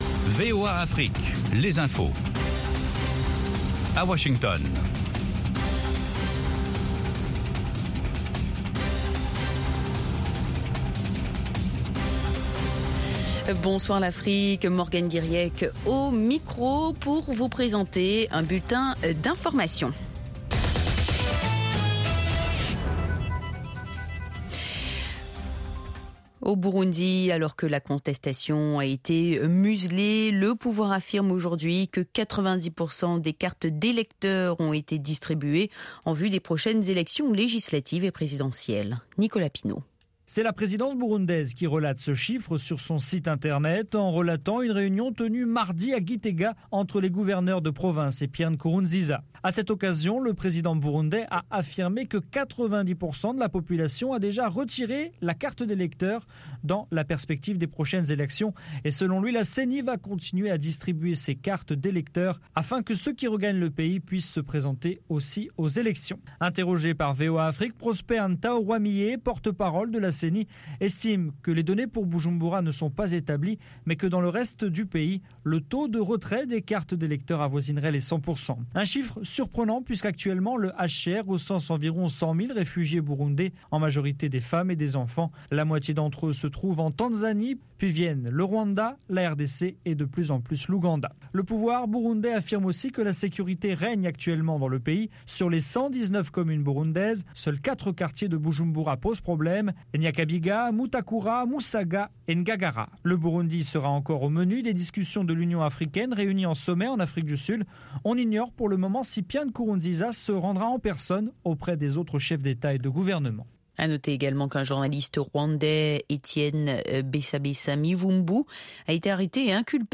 Newscast